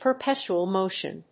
アクセントperpétual mótion